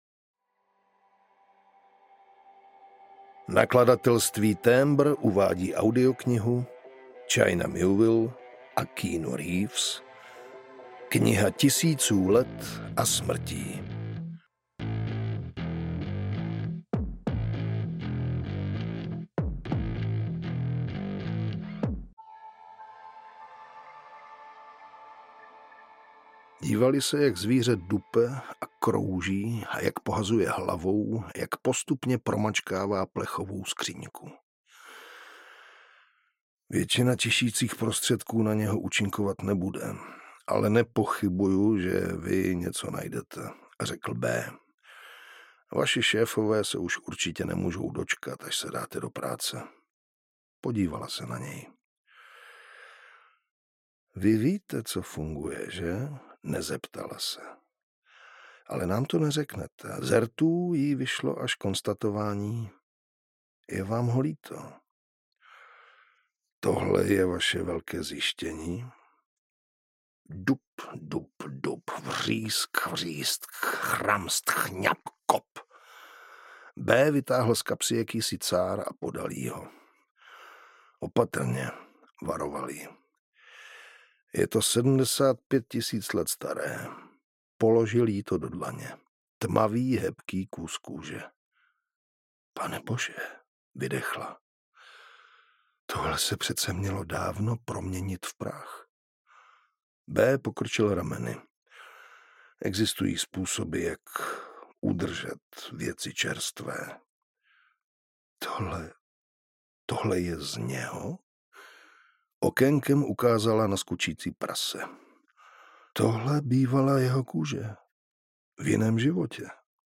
Kniha tisíců let a smrtí audiokniha
Ukázka z knihy
• InterpretJiří Vyorálek